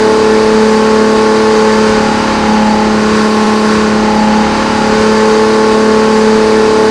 supra_low.wav